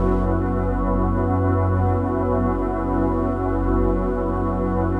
DM PAD1-10.wav